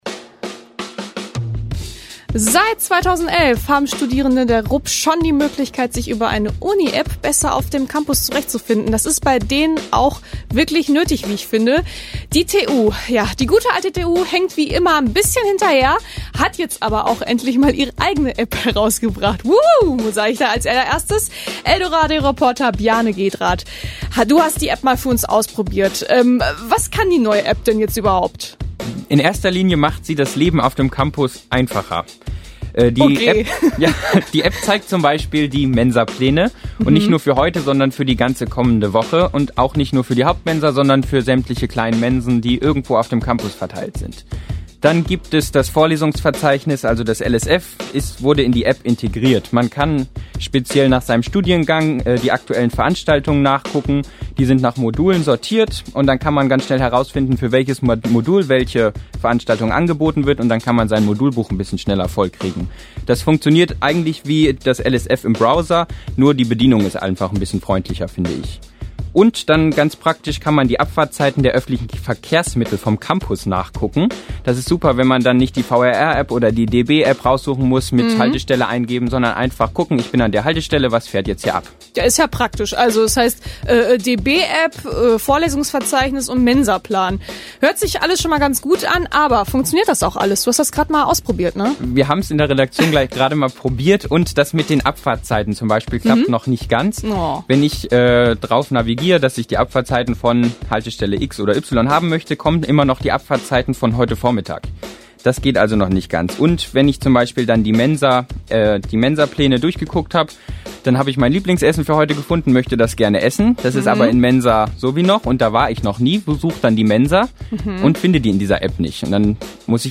Serie: Kollegengespräch